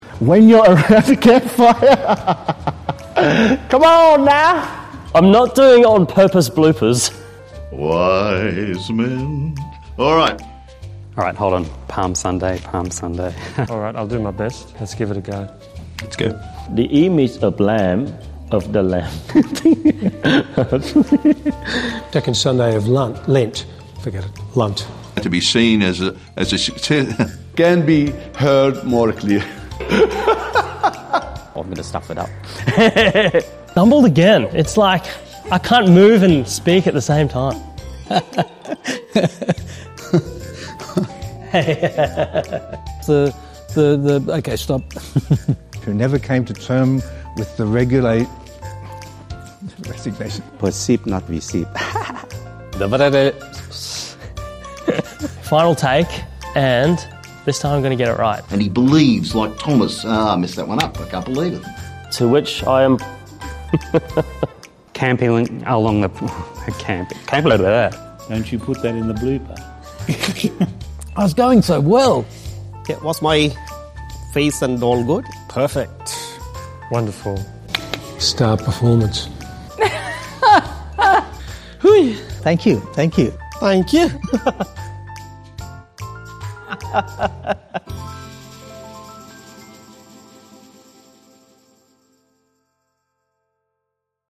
Two-Minute Homily and Reflection Bloopers 2025
Because even when we share God’s Word, there are plenty of moments filled with laughter, stumbles and surprises. So sit back, smile and enjoy this year’s bloopers, a joyful reminder that God works through us, imperfections and all!